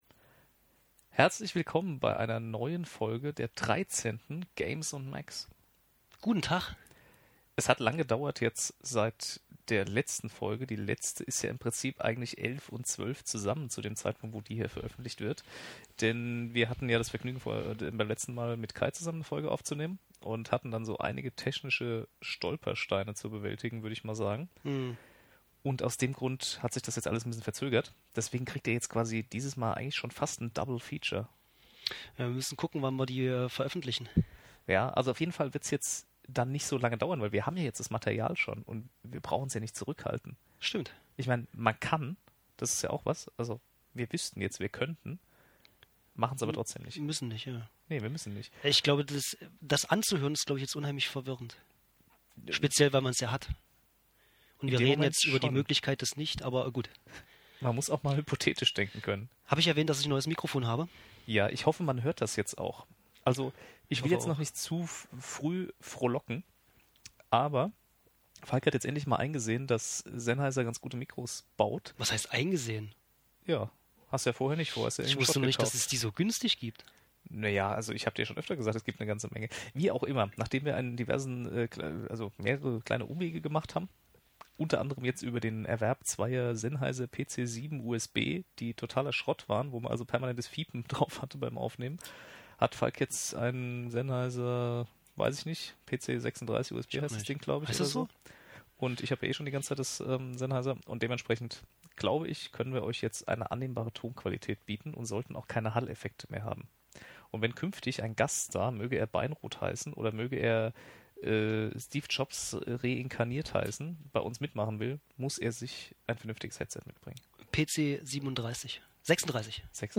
Dieses Mal ohne Gast, aber dafür mit krasser neuer Technik.